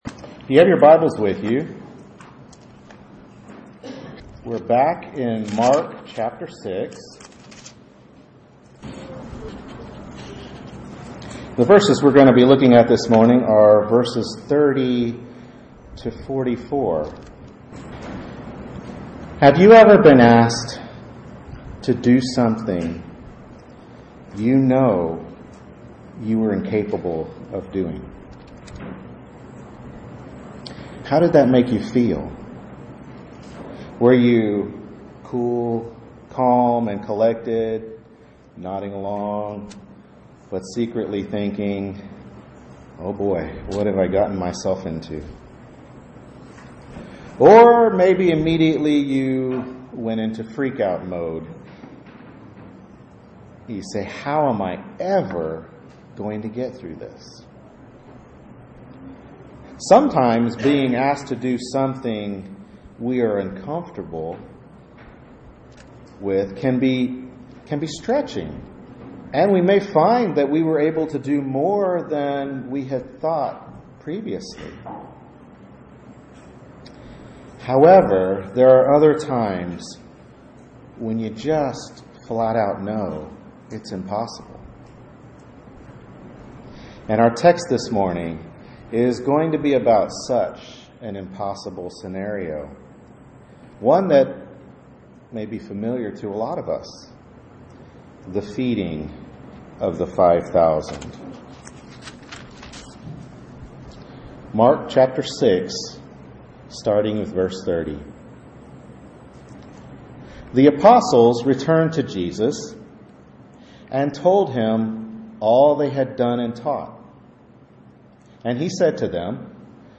Mark 6:30-44 Service Type: Morning Worship Service Bible Text